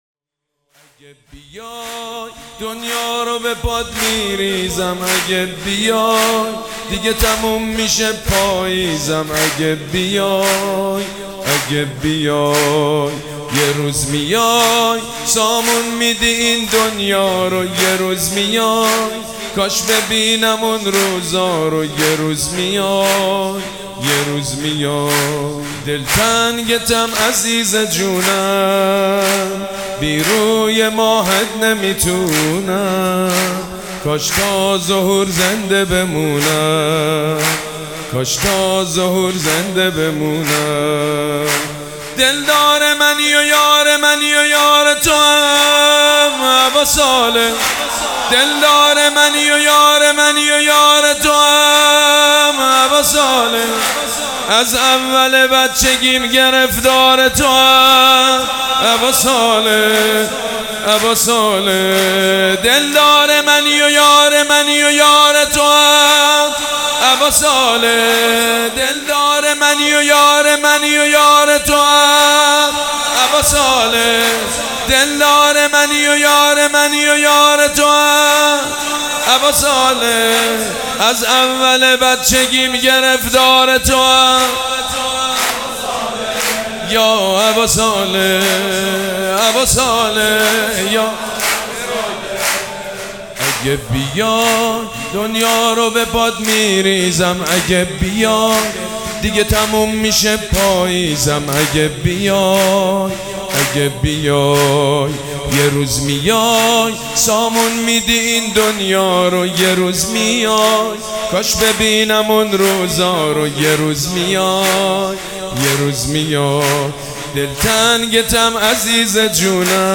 سرود
جشن نیمه شعبان